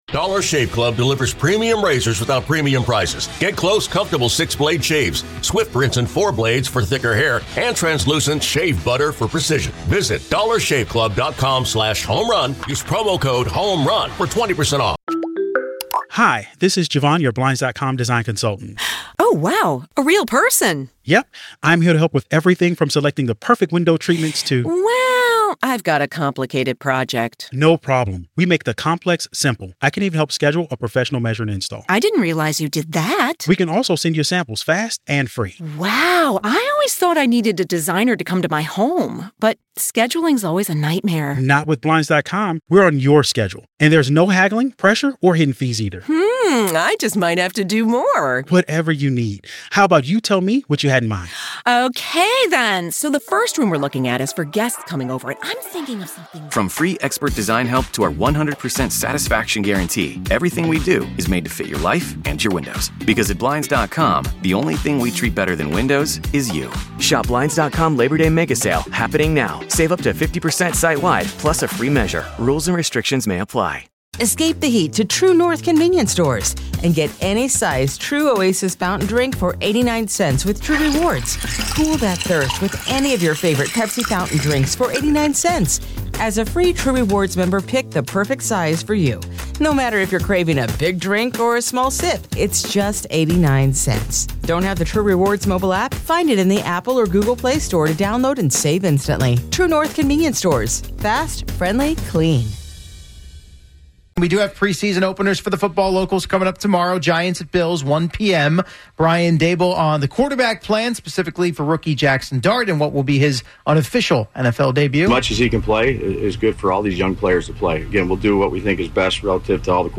Boomer Esiason and Gregg Giannotti talk sports and interview the hottest names in sports and entertainment.
A caller argues that Jayden Daniels is better than Caleb Williams.